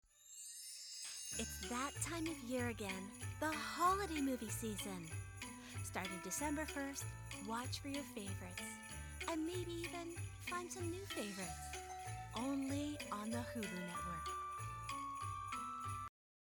Female
Christmas Holiday Tv Promo